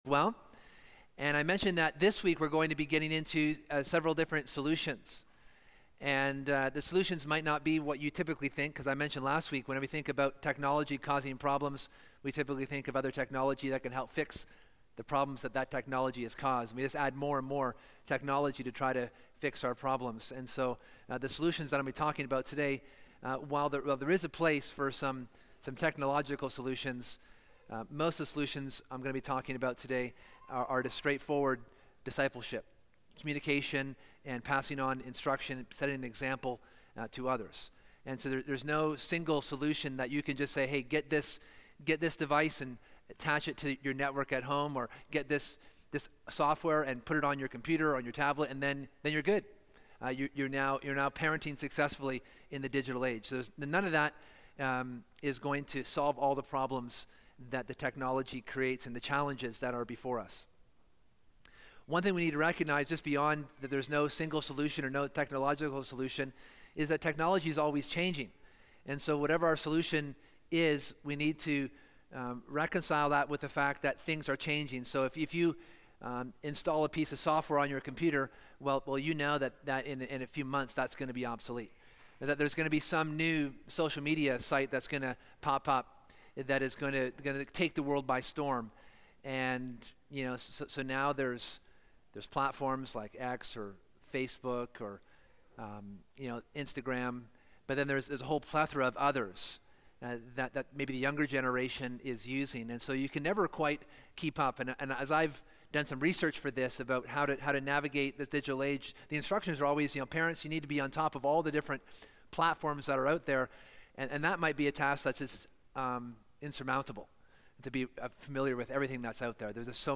In this final sermon in the series we consider the law’s usefulness for the believer saved by grace.